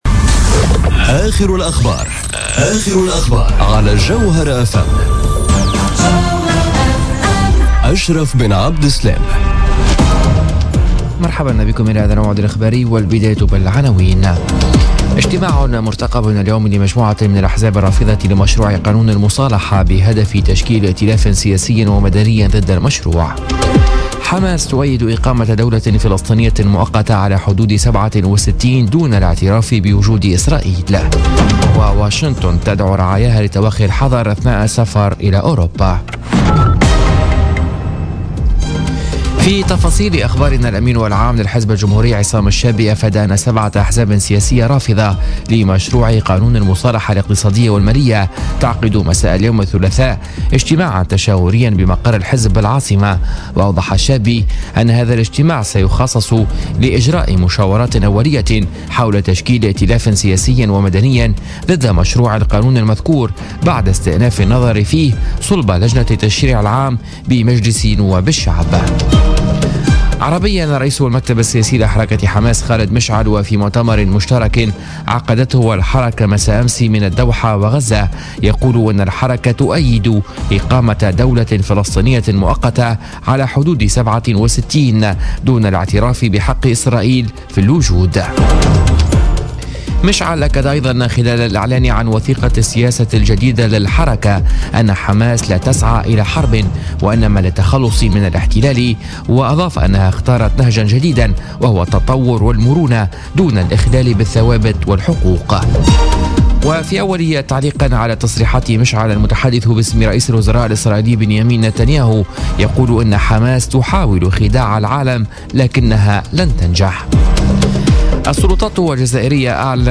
نشرة أخبار منتصف الليل ليوم الثلاثاء 2 ماي 2017